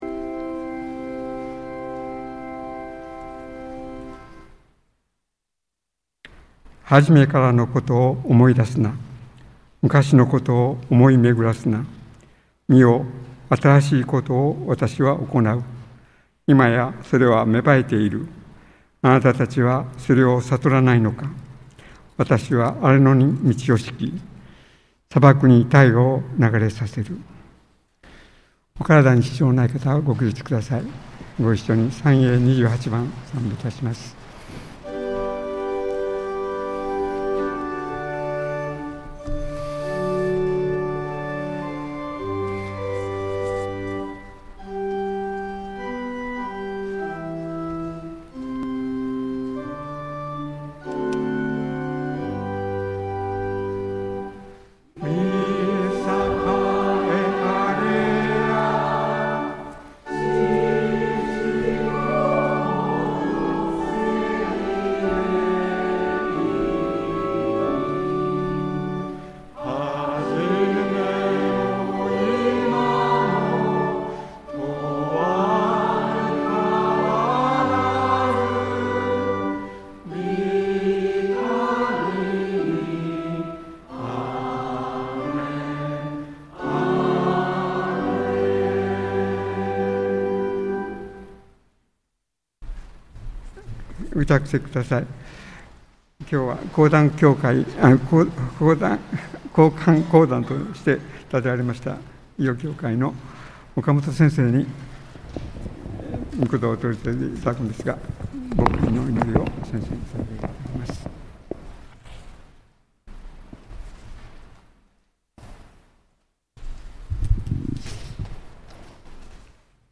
2025年2月16日 日曜礼拝（音声）